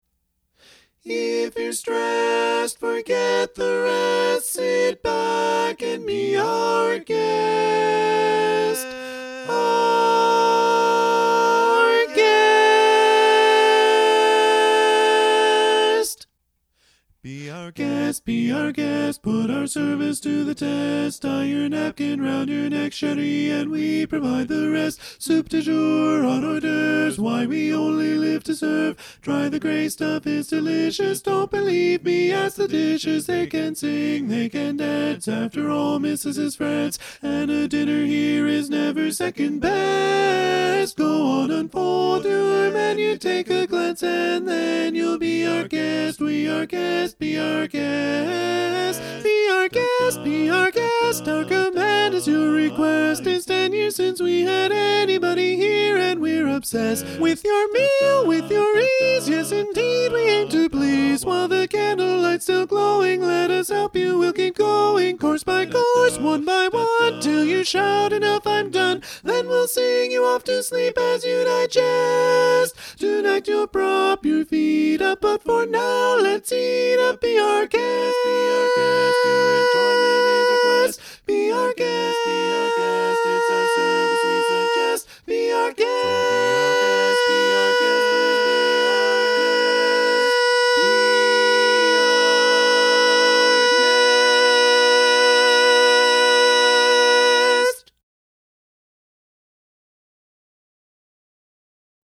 Up-tempo
B♭ Major
Tenor